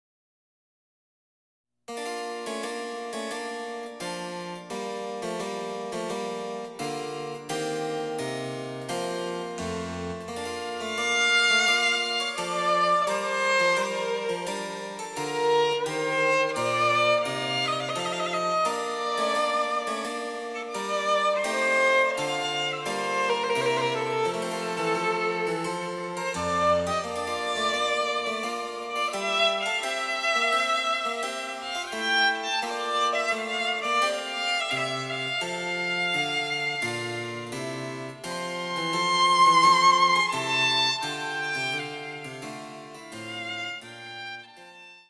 最後に少し盛り上がりをはぐらかして終わります。
■ヴァイオリンによる演奏
チェンバロ（電子楽器）演奏